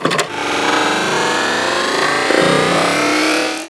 mine_charge.wav